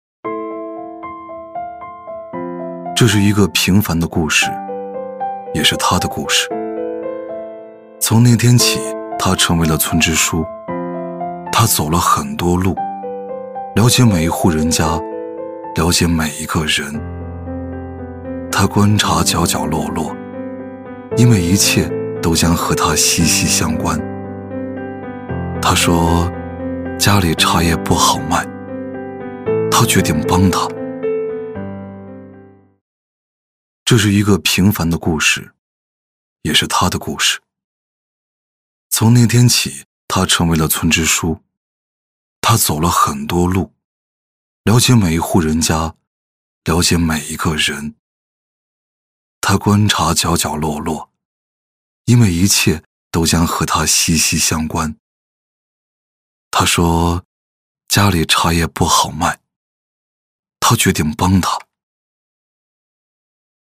C男148号 | 声腾文化传媒
【旁白】走心 他的故事.mp3